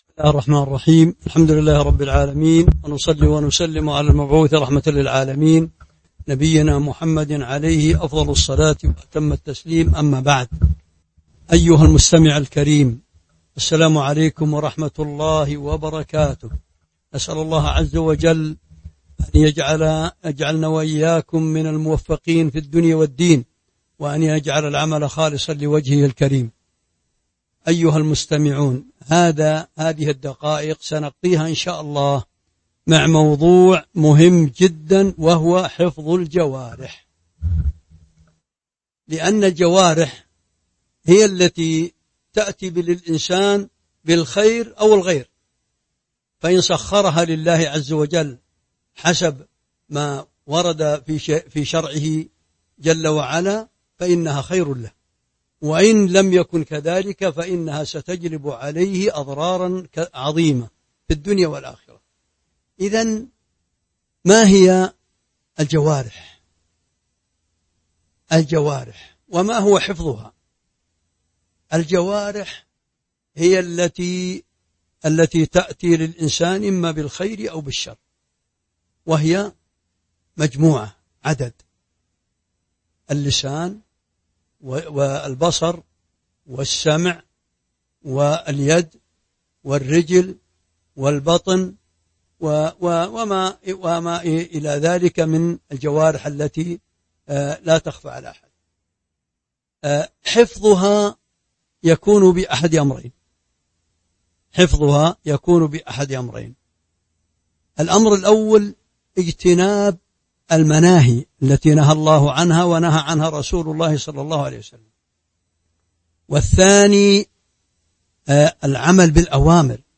تاريخ النشر ١٨ ذو الحجة ١٤٤٢ هـ المكان: المسجد النبوي الشيخ